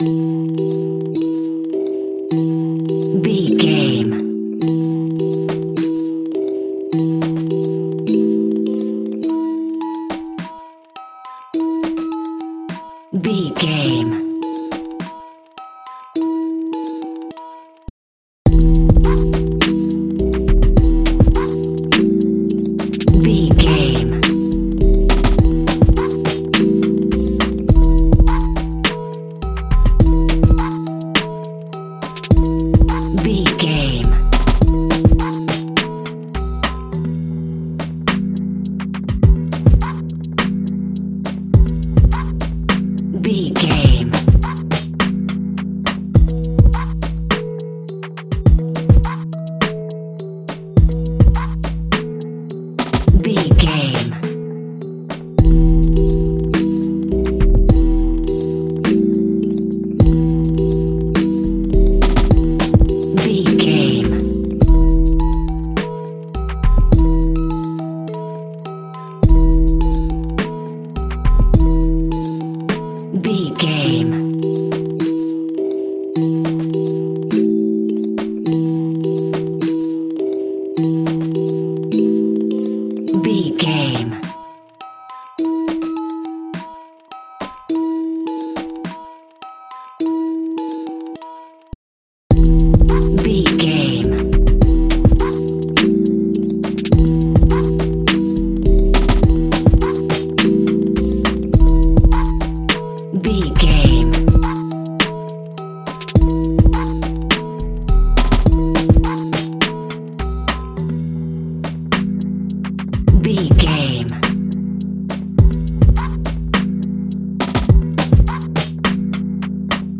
Aeolian/Minor
aggressive
intense
driving
dark
drum machine
synthesiser
percussion